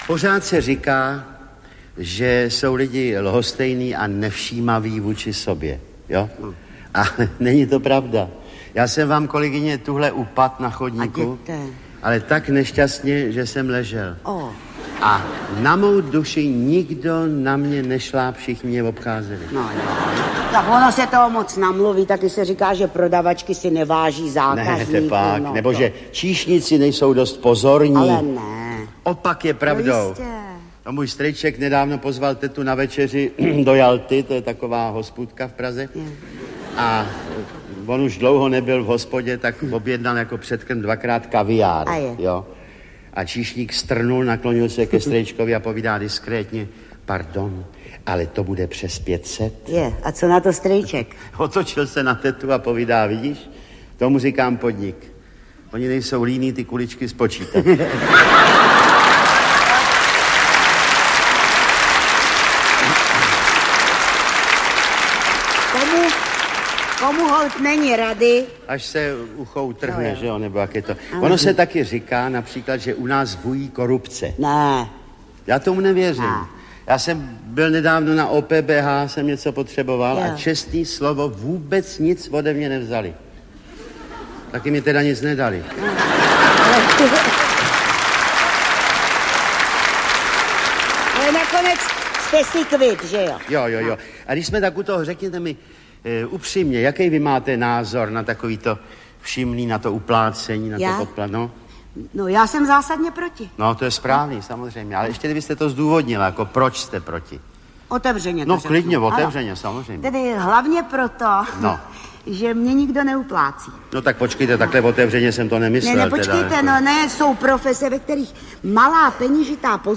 Vladimír Dvořák a Jiřina Bohdalová v Televarieté 2 - je humoristická televízna show vo skvelom hereckom obsadení
Ukázka z knihy